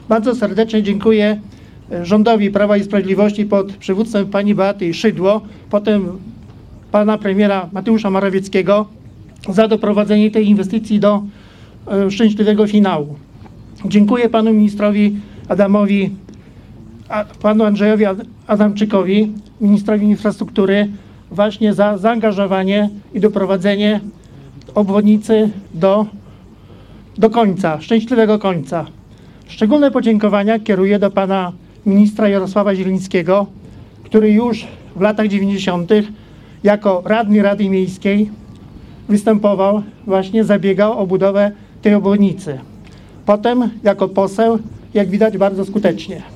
W imieniu samorządów Suwalszczyzny głos zabrał Witold Kowalewski, starosta suwalski, który dziękował przedstawicielom rządu za budowę drogi.